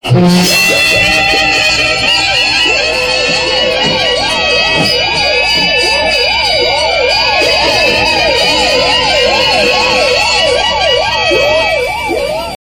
Play TEC 5 Seconds Win Alarm - SoundBoardGuy
the_electric_comapny_5_second_win_alarm_from_1977.mp3